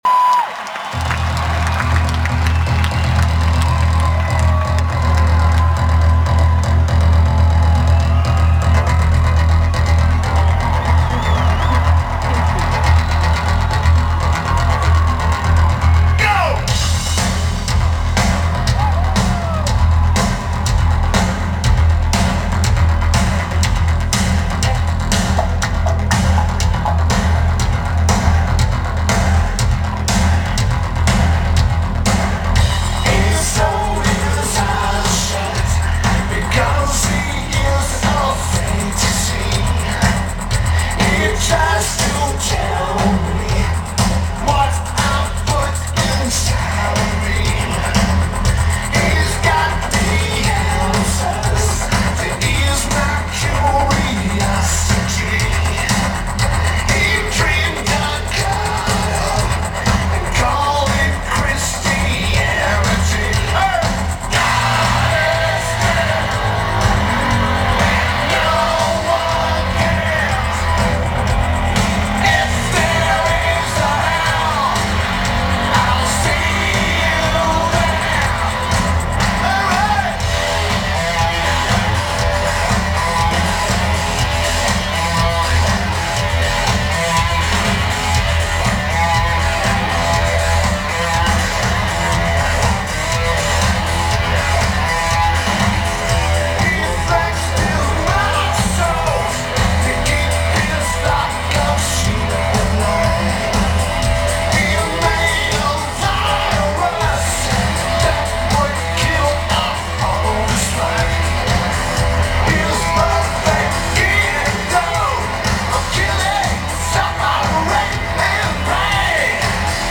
Independence Day Festival
Lineage: Audio from Video - AUD (JVC GR-D340)